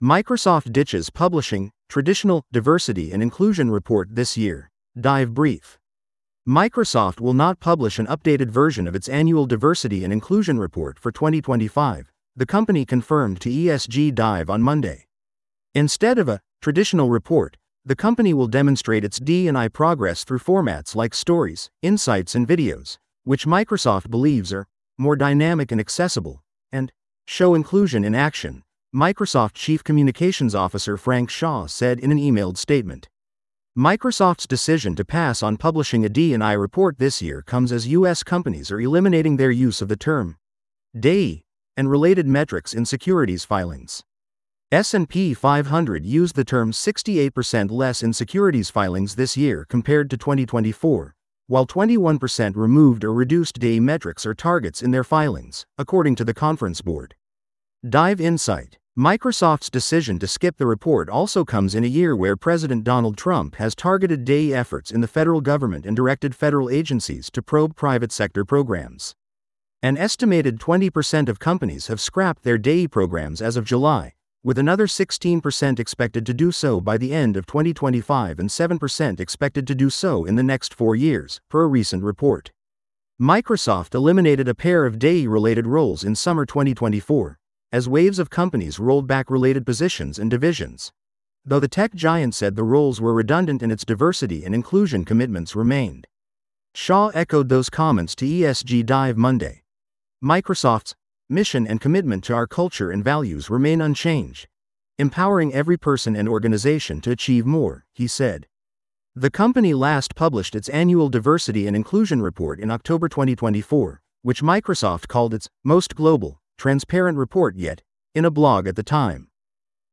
This audio is auto-generated. Please let us know if you have feedback.